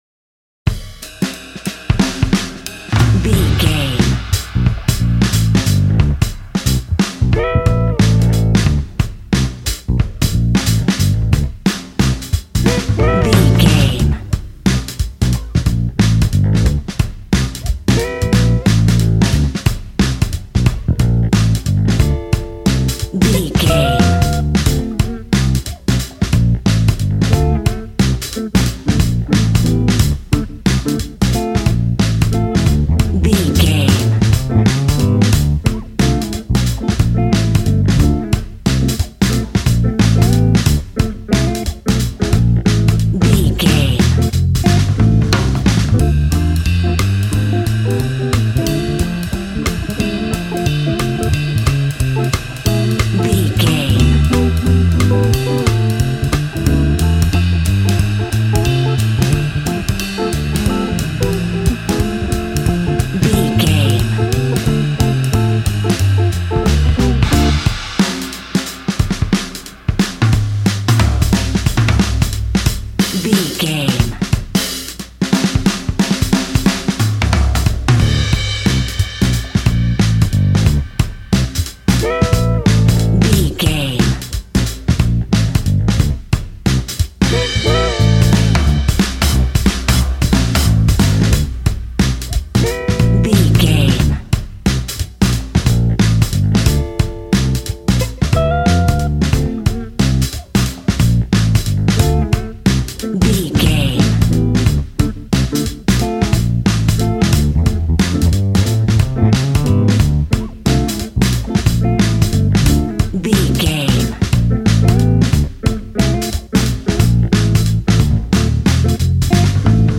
Aeolian/Minor
relaxed
smooth
synthesiser
drums
80s